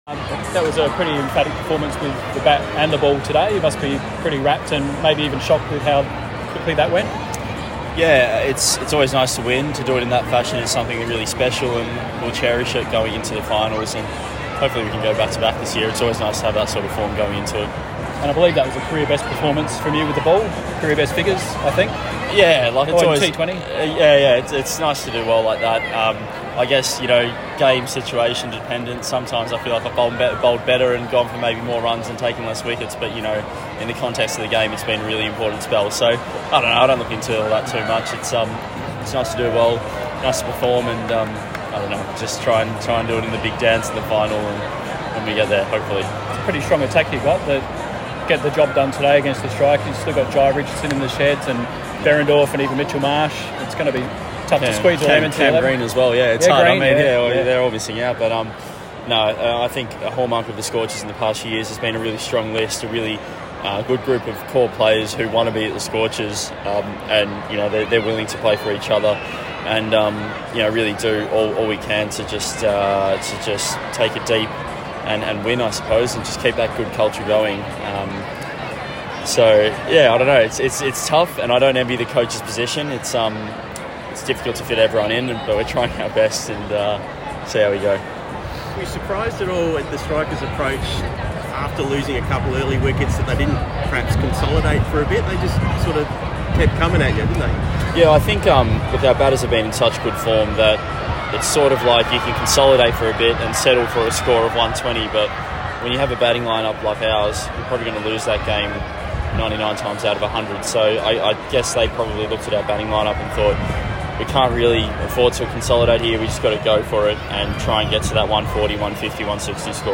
spoke to media after the Scorchers seven-wicket victory over the Adelaide Strikers at the Adelaide Oval.